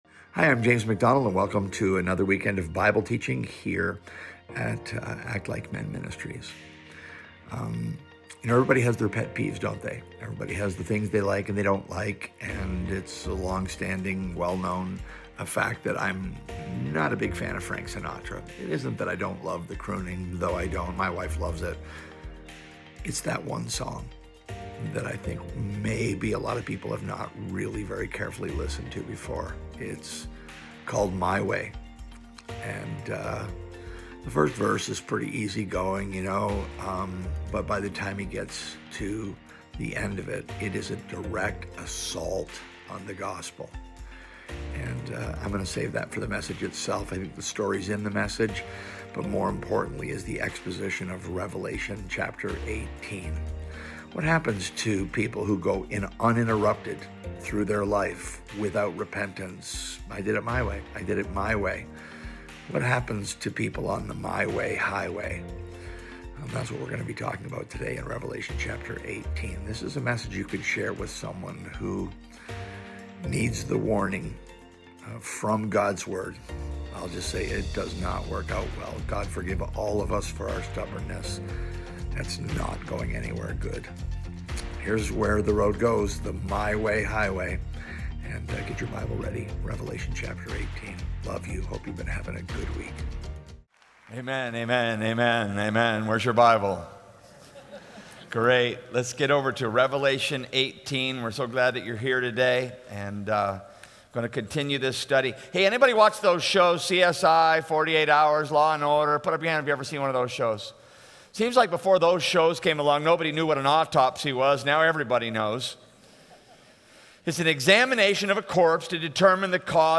Listen to this teaching in Revelation 18 and ask the Spirit to search your heart.